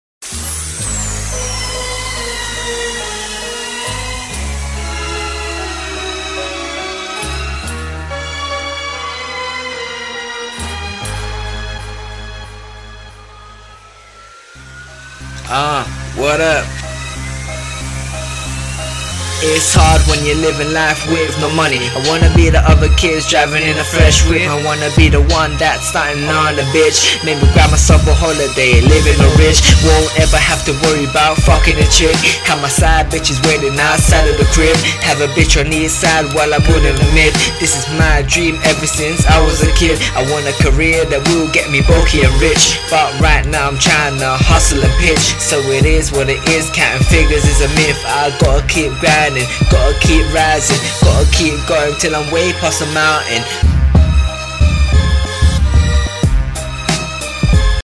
I need feedback about the quality and the rapping